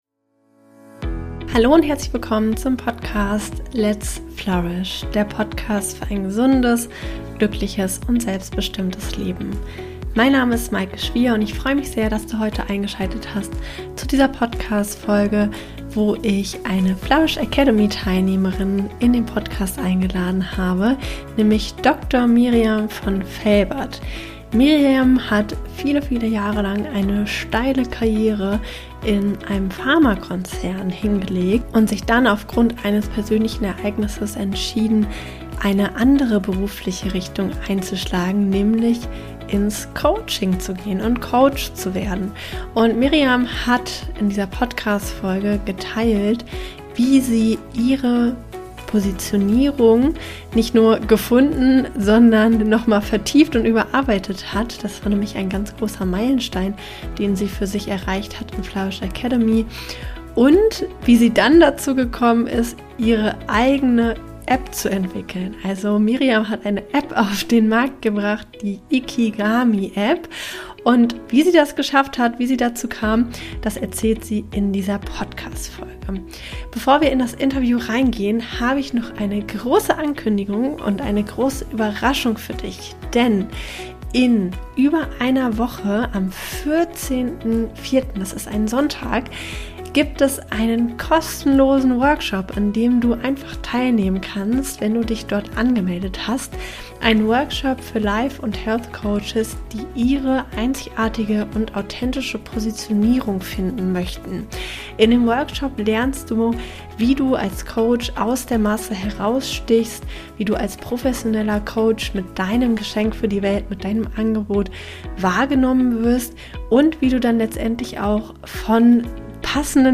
Wie finde ich als Coach meine authentische Positionierung? | Interview